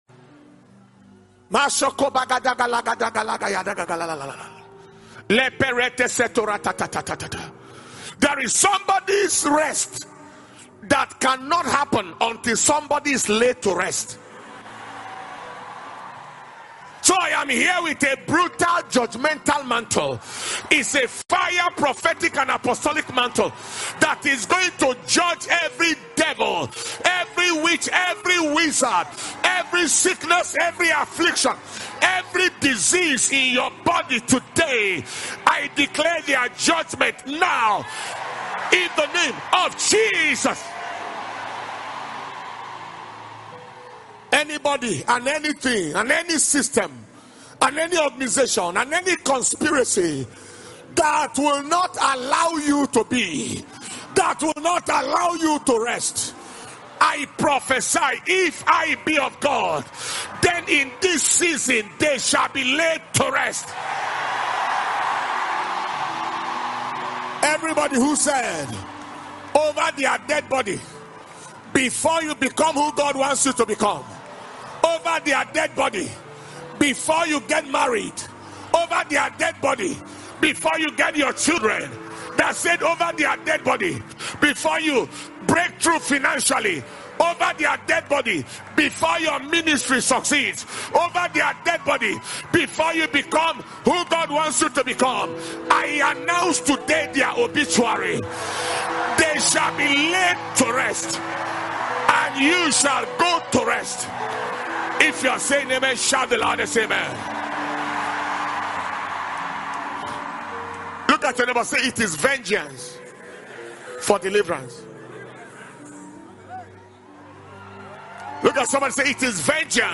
Healing And Deliverance Service – Tuesday, 17th August 2021